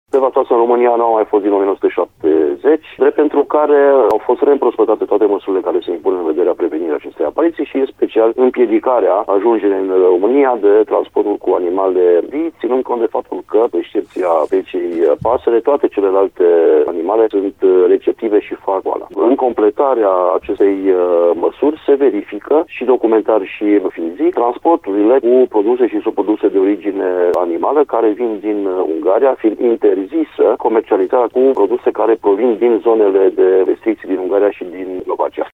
În acest sens, se are în vedere testarea efectivelor de animale şi posibilitatea vaccinării în fermele în care s-au făcut importuri, spune directorul executiv al Direcției Sanitare-Veterinare și pentru Siguranța Alimentelor Timiș Flavius Nicoară.